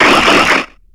Cri de Vibraninf dans Pokémon X et Y.